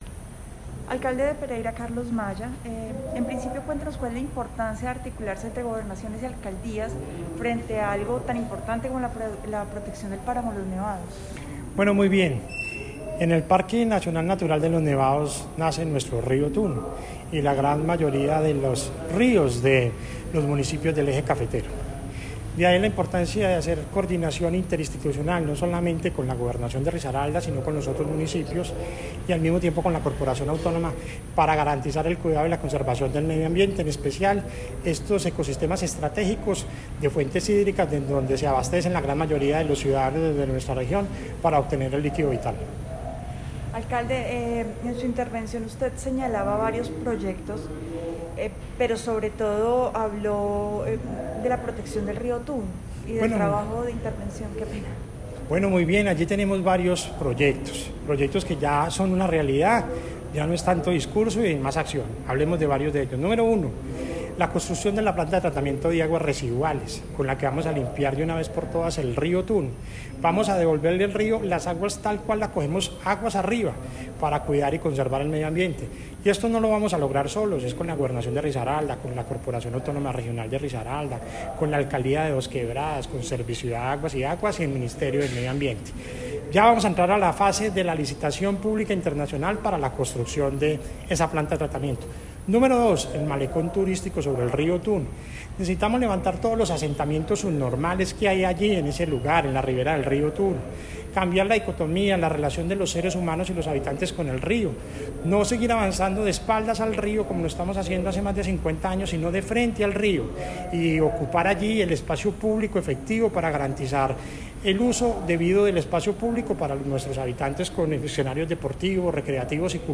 Por su parte, el alcalde de Pereira Carlos Maya, expuso ampliamente el trabajo que viene desarrollando, en articulación con CARDER, en función de la protección de las fuentes hídricas, en especial el río Otún.
Escuchar Audio: Carlos Alberto Maya, Alcalde de Pereira.